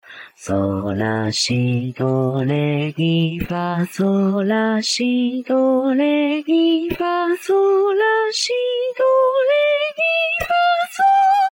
性別：男
藤咲透明_JPVCV_優しい（gentle）               DL
收錄音階：G3